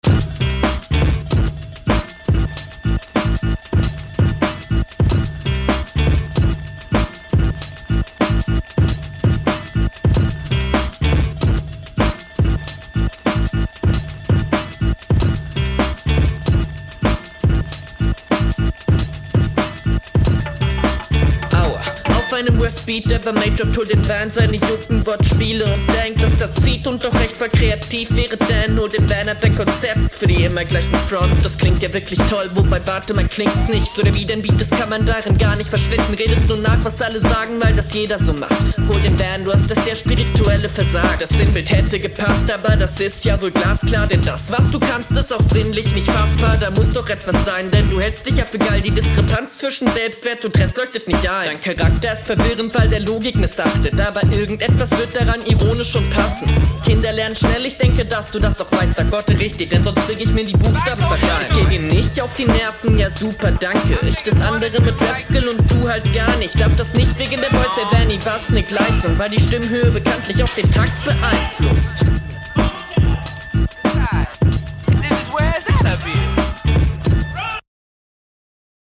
Sehr höhenfokusierter Mix mit ner sehr höhenfokusierter Stimme, dadurch wirkt der nicht so druckvolle stimmeinsatz …